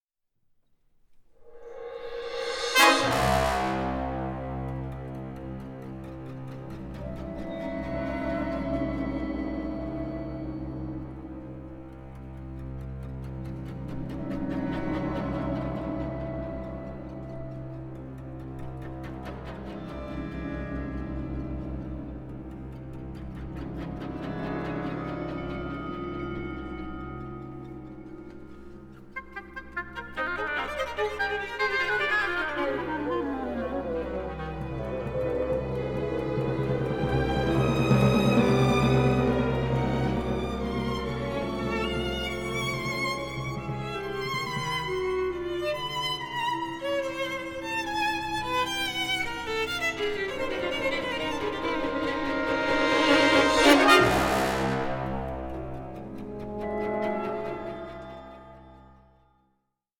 for Oboe, Violin and Orchestra